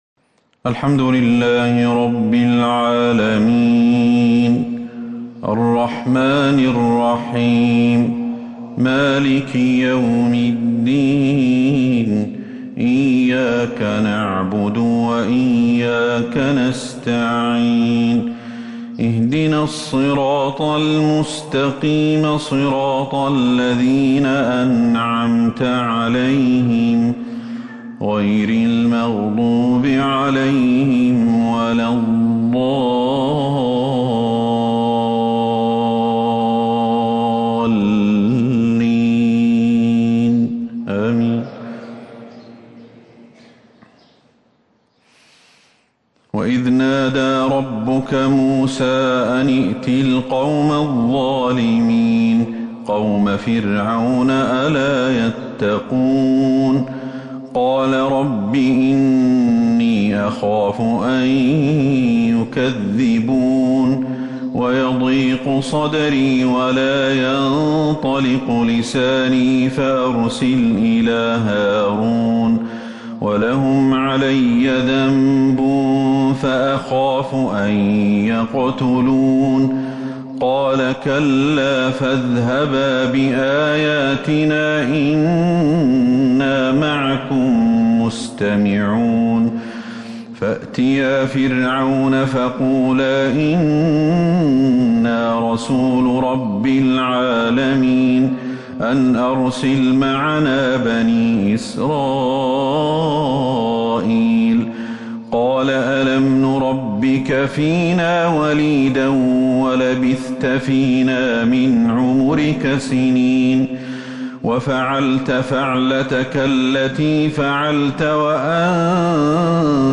فجر الخميس 11 محرم 1443 هـ ما تيسر من سورة {الشعراء} > 1443 هـ > الفروض